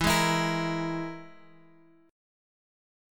EMb5 chord {x 7 6 x 5 6} chord
E-Major Flat 5th-E-x,7,6,x,5,6.m4a